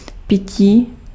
Ääntäminen
France (Ouest): IPA: [bwaːʁ]